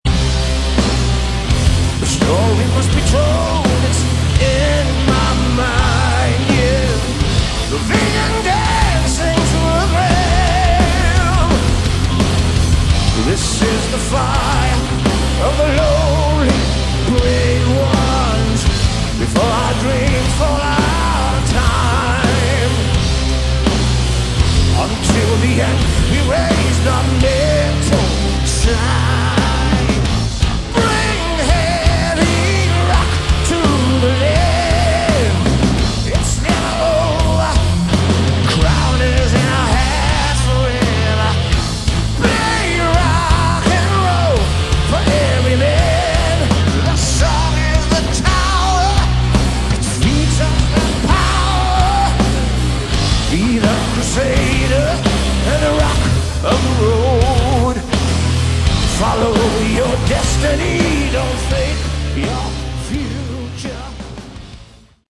Category: Melodic Metal
vocals
guitar
keyboards
bass
drums